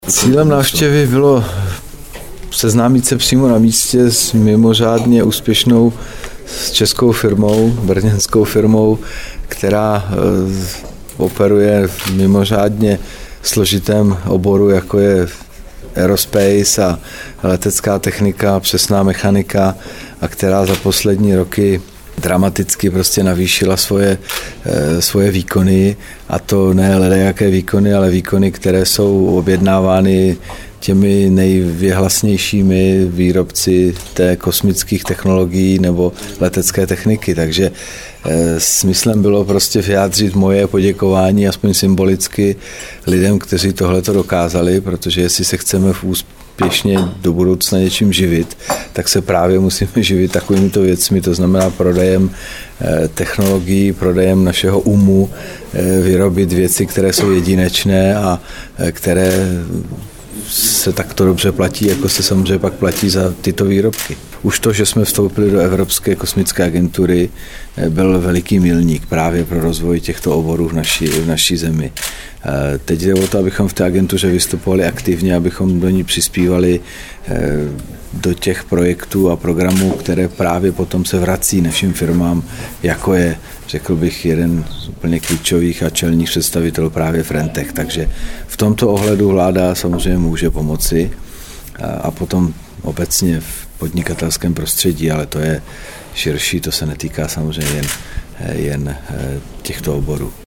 Premiér k návštěvě brněnského podniku Frentech Aerospace, 17. září 2013